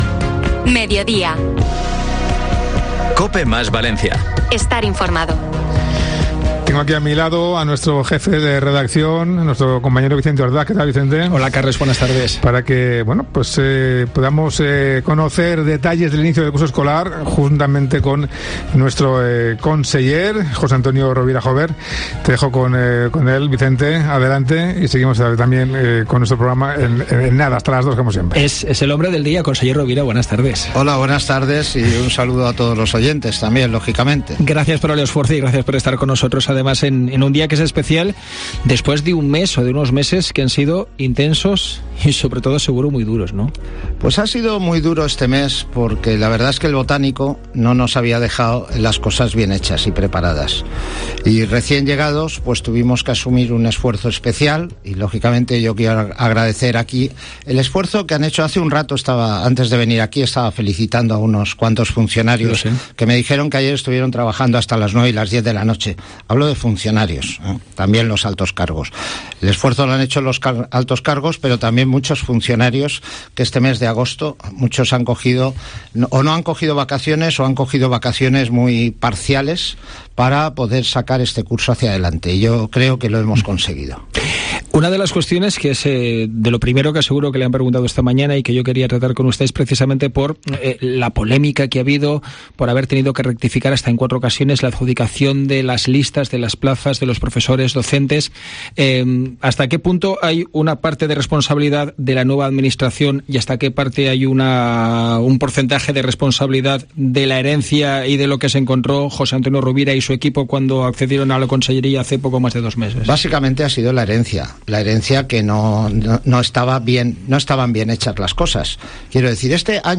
Mediodía COPE MÁS Valencia | Entrevista Conseller de Educación